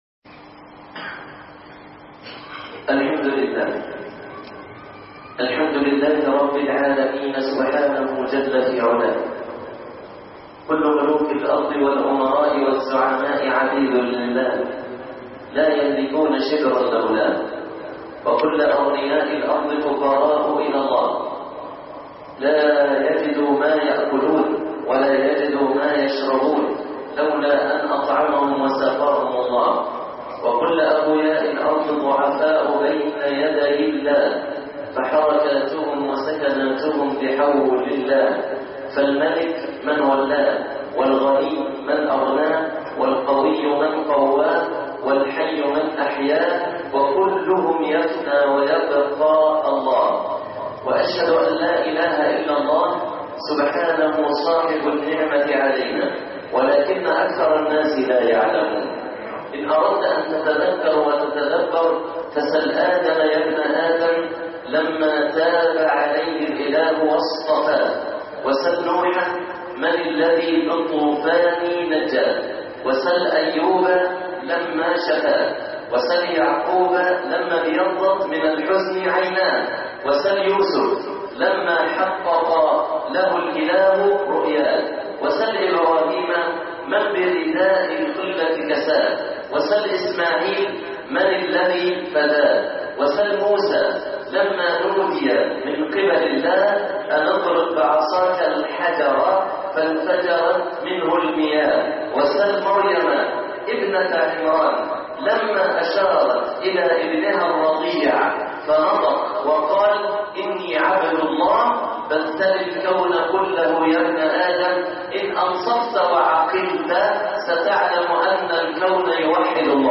خطب الجمعه